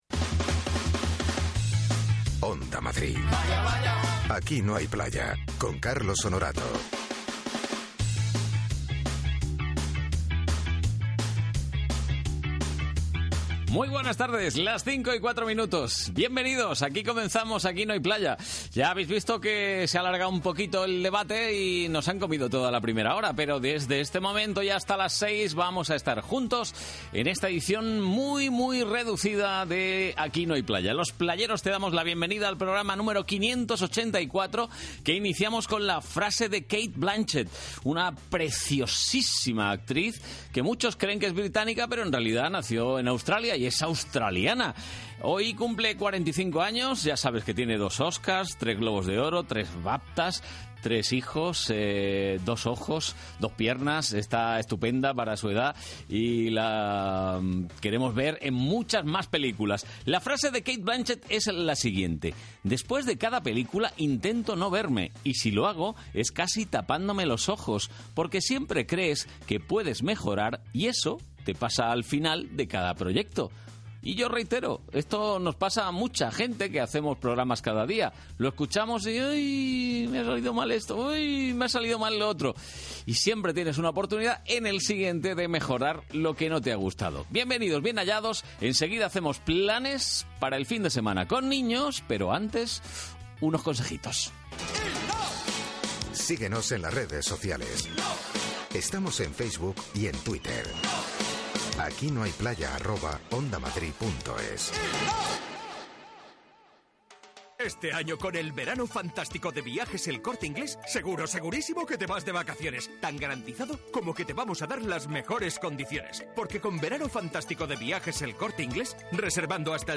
Mi intervención va desde el minuto 16.00 hasta el 31.45